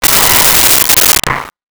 Rocket Launcher Sci Fi 03
Rocket Launcher Sci Fi 03.wav